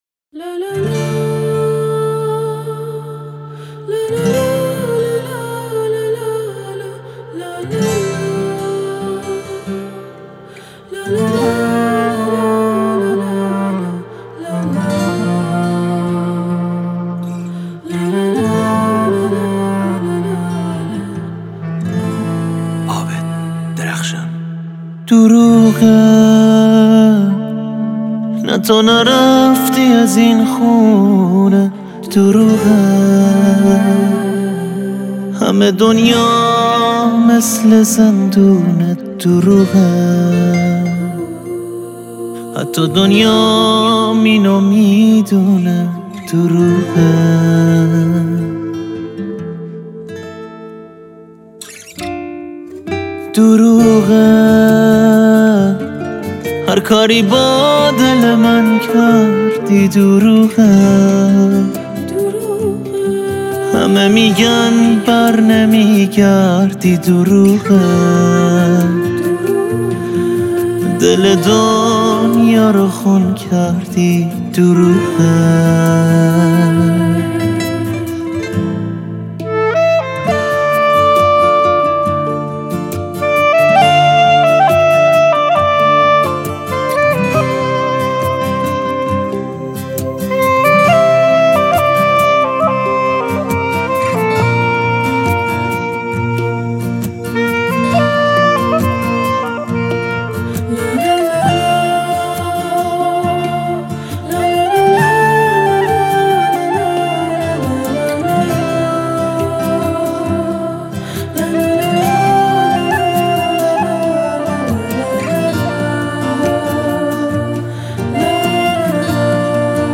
گیتار
کرال